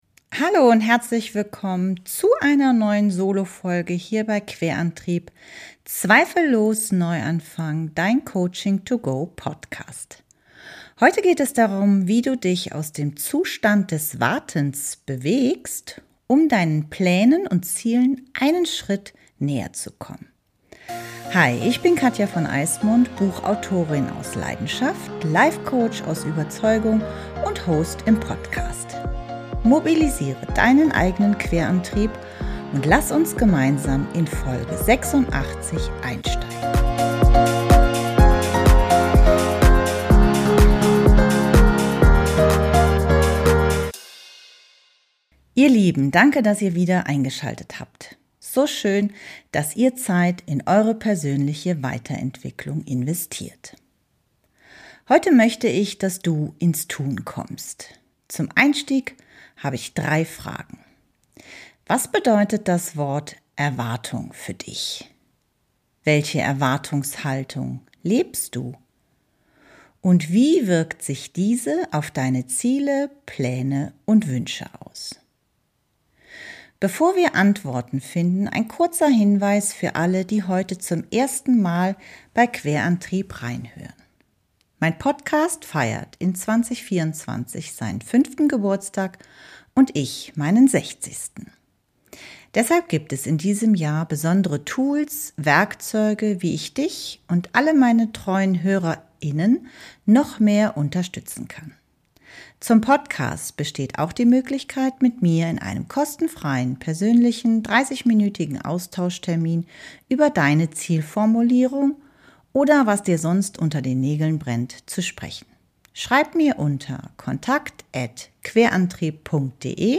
In der motivierenden Solofolge erfährst du, wie du dich aus dem Zustand des Wartens bewegst, um deinen Plänen und Zielen einen Schritt näher zu kommen.